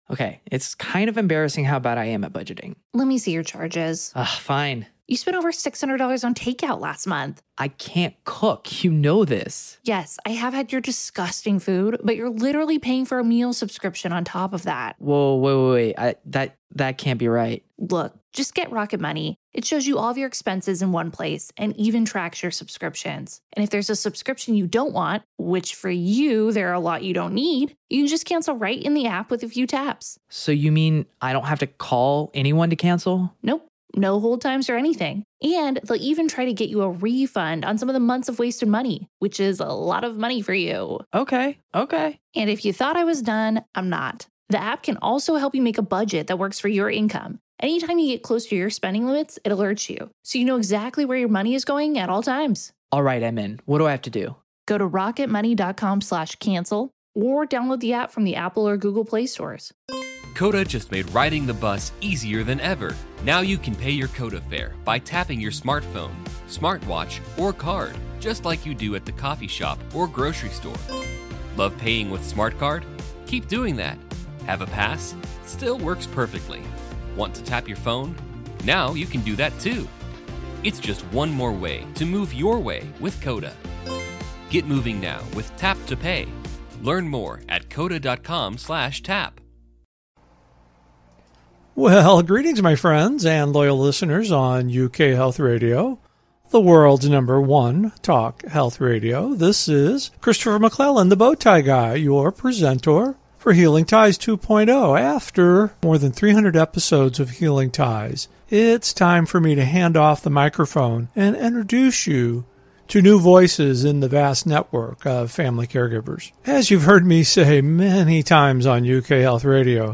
In each episode, we interview one of our authors who may have written a memoir, caregiver guide, novel, children’s book, activity guide, poetry book, or blog.